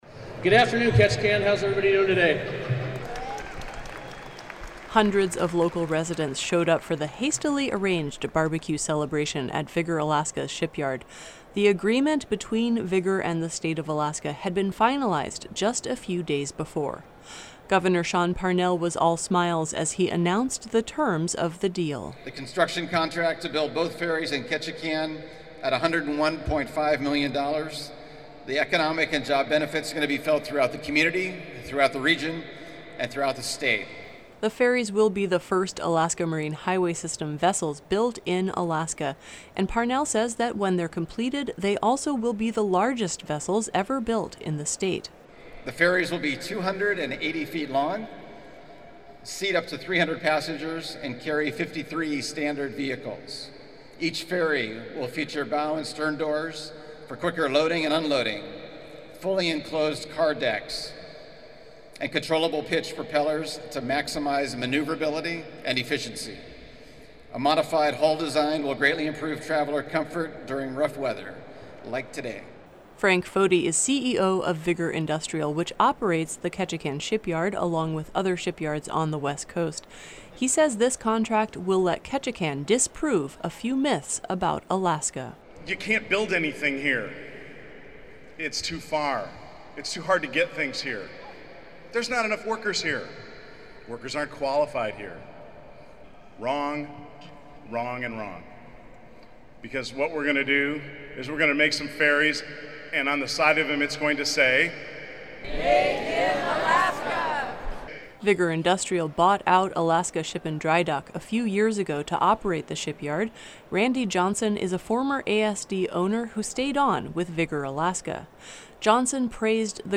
It’s official: The Ketchikan Shipyard will build two new ferries for the State of Alaska over the next few years. The deal was announced on a very rainy Saturday during a barbecue at the shipyard’s huge, enclosed ship construction area.
Hundreds of local residents showed up for the hastily arranged barbecue celebration at Vigor Alaska’s shipyard.